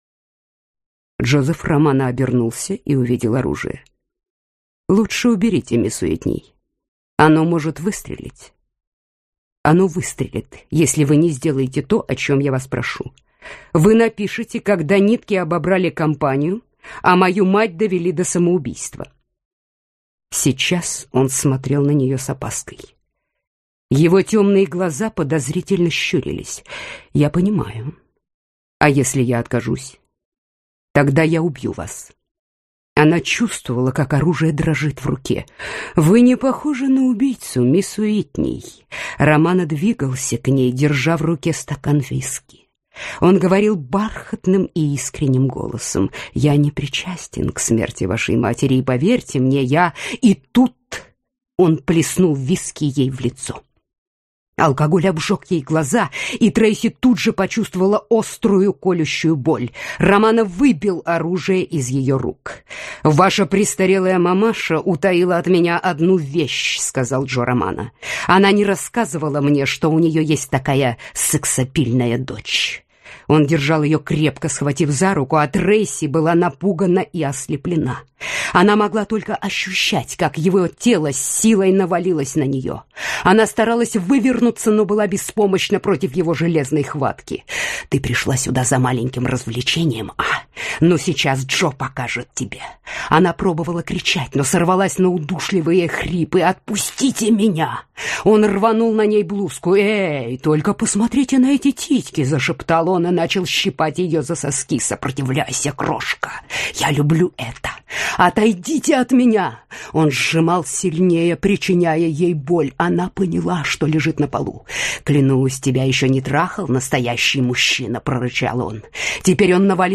Аудиокнига Если наступит завтра - купить, скачать и слушать онлайн | КнигоПоиск